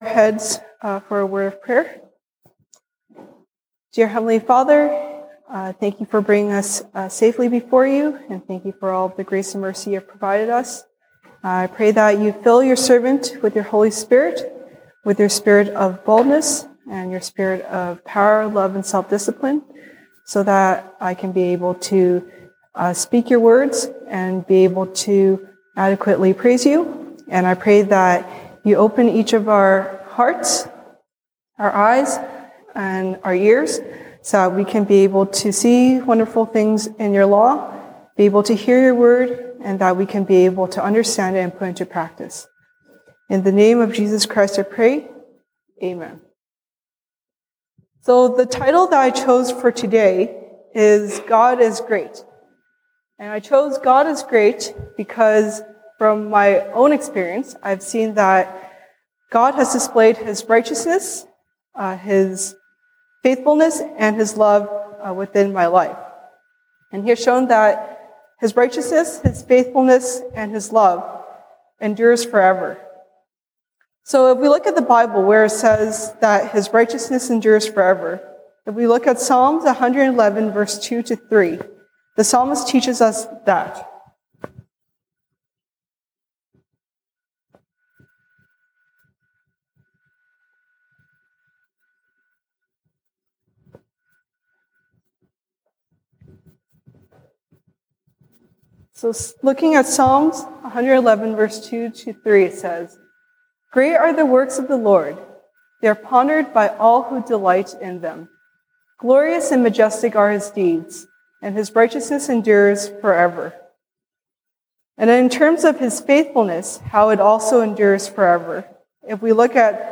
西堂證道 (英語) Sunday Service English: God is Great
Passage: 詩篇 Psalms 116:1-19 Service Type: 西堂證道 (英語) Sunday Service English